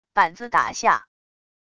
板子打下wav音频